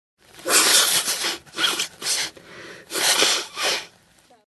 Звуки шмыганья носом